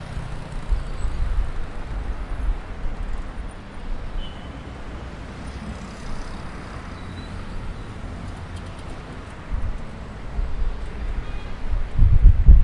描述：波哥大直布罗陀公共街道（“Avenida 19”）的交通（哥伦比亚）以XY技术（44.1 KHz）排列的Zoom H4录制
Tag: 音景 环境 交通 现场录音 城市